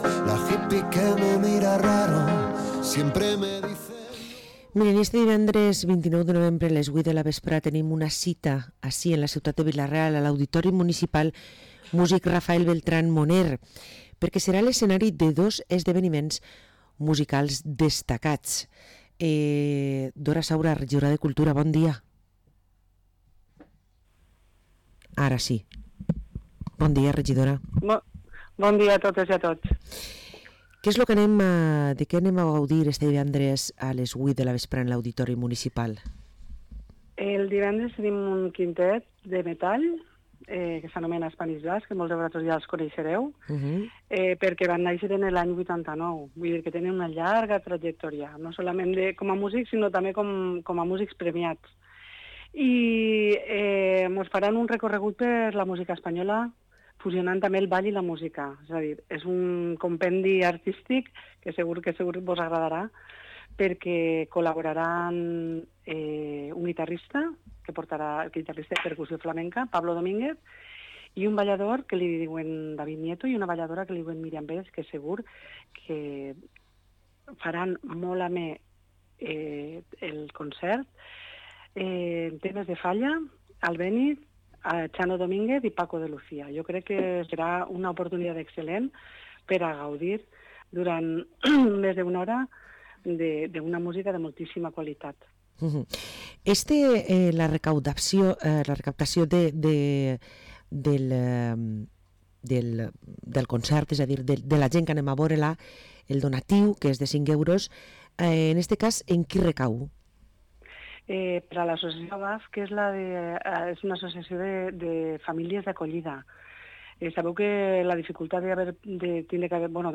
Parlem amb Dora Saura, regidora de Cultura a l´Ajuntament de Vila-real